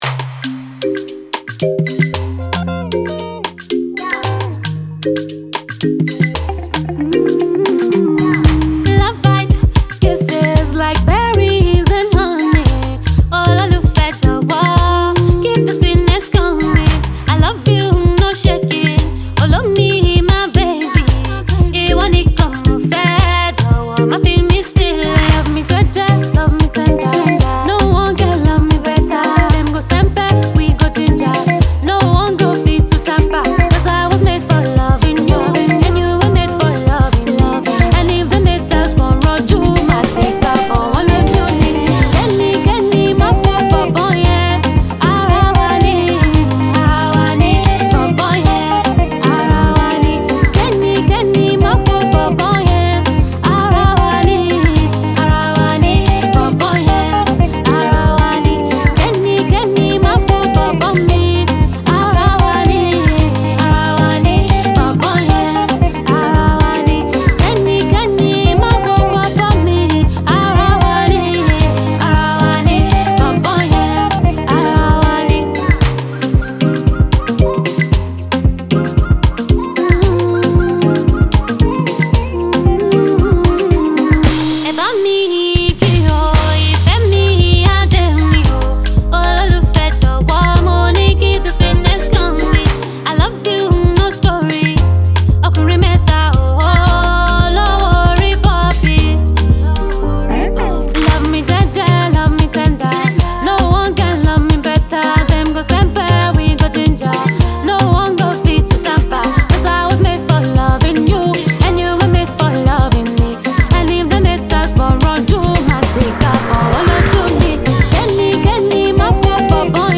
Afro-pop/Afro-soul
a melodious and catchy baseline